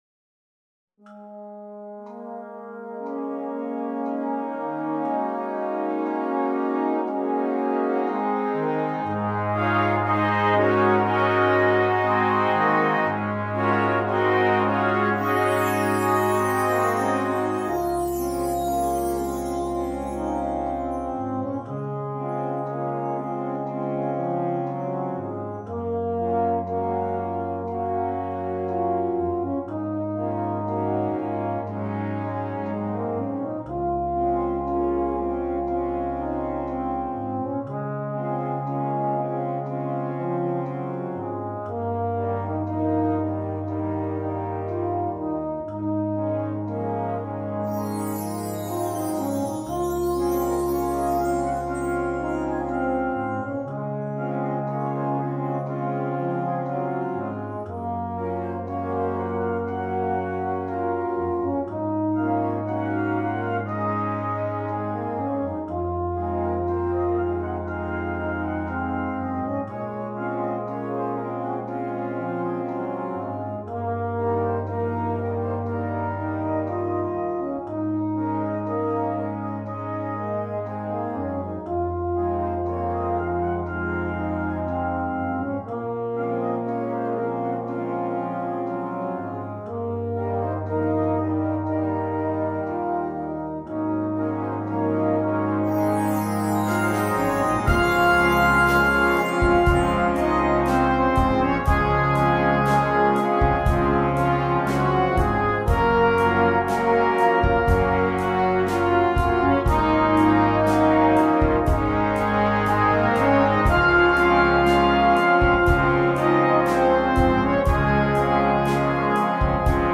2. Brass Band
Full Band
without solo instrument
Entertainment